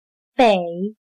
/běi/norte